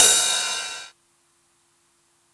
• Drum Ride D Key 01.wav
Royality free drum ride sample tuned to the D note. Loudest frequency: 6880Hz
drum-ride-d-key-01-OMn.wav